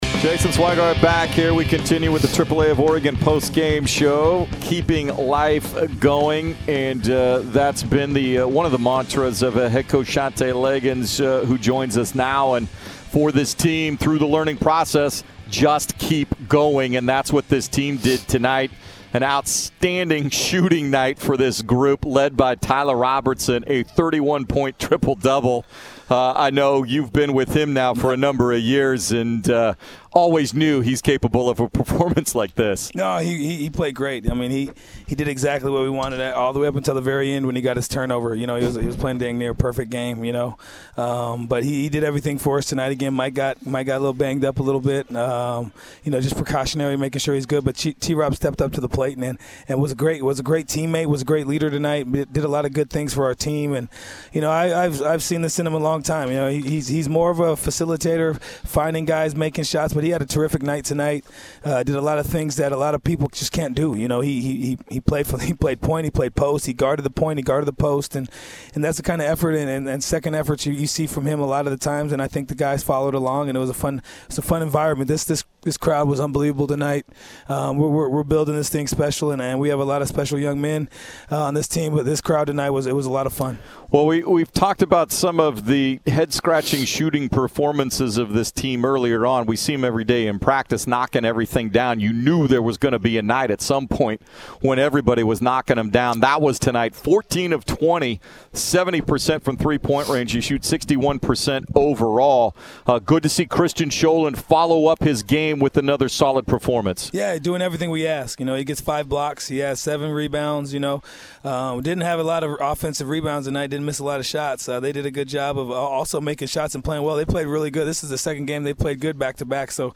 Postgame Radio Interviews vs. LMU
Courtesy 910 ESPN Portland (KMTT).